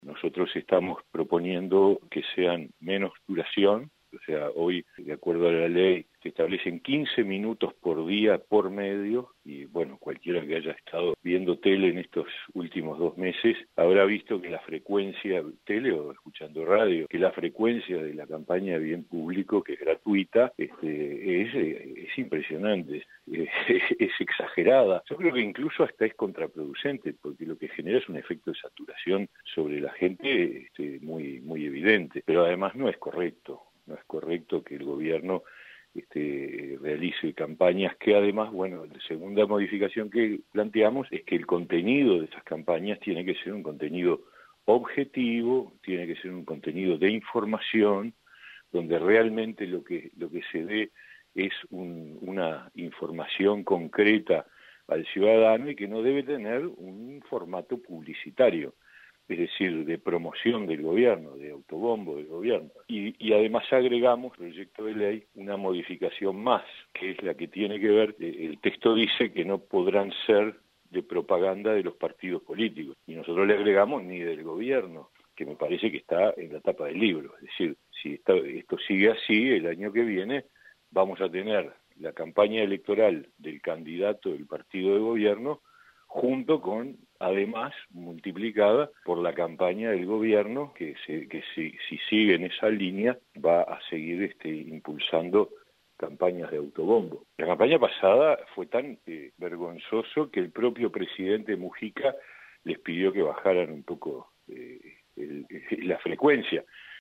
Escuche a Mieres aquí: